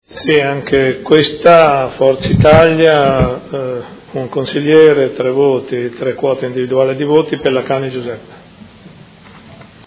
Sindaco — Sito Audio Consiglio Comunale